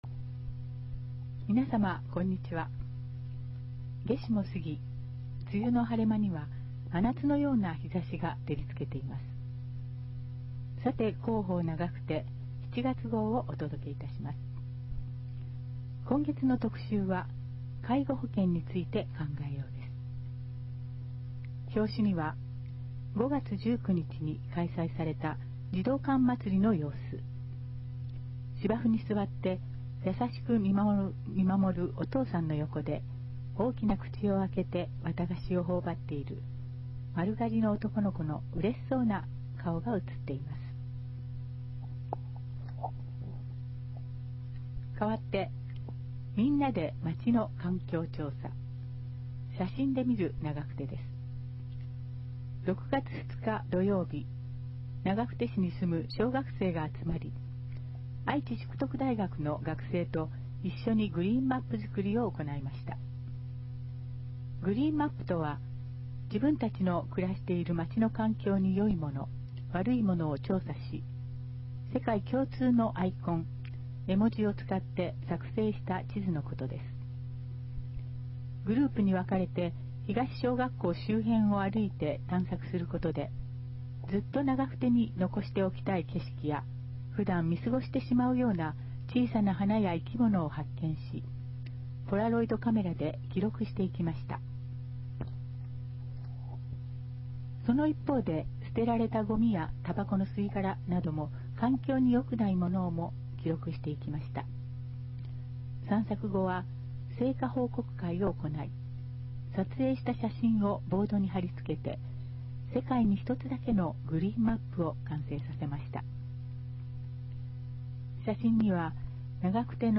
平成29年8月号から、ボランティア団体「愛eyeクラブ」の皆さんの協力により、広報ながくてを概要版として音声化して、ホームページ上で掲載しています。
音声ファイルは、カセットテープに吹き込んだものをMP3ファイルに変換したものです。そのため、多少の雑音が入っています。